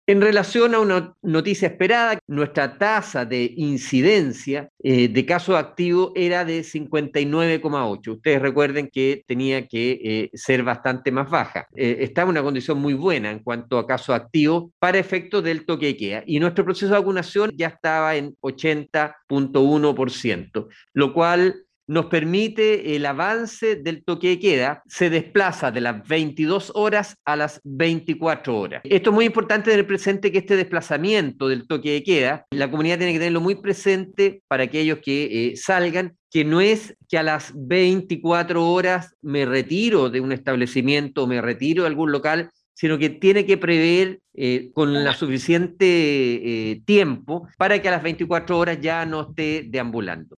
Respecto a los cambios en el toque de queda la autoridad de salud, señaló: